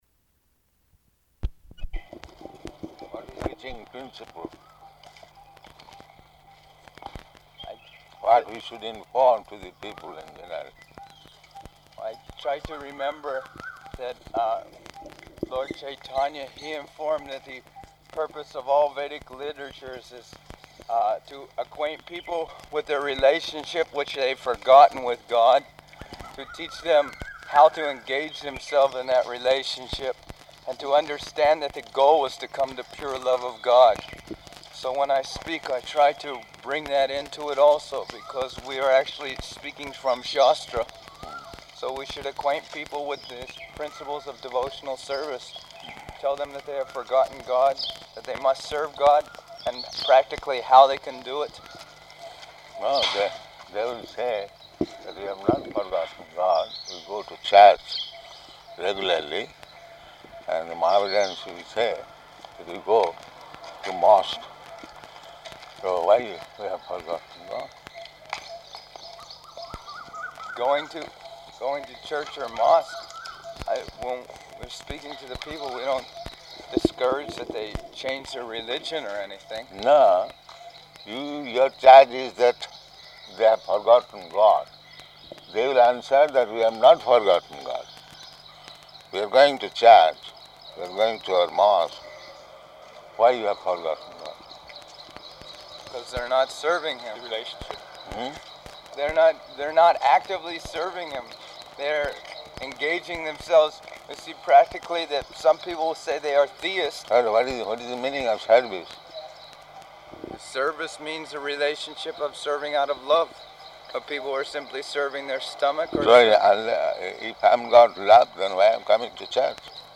Morning Walk --:-- --:-- Type: Walk Dated: April 22nd 1974 Location: Hyderabad Audio file: 740422MW.HYD.mp3 Prabhupāda: What is preaching principle?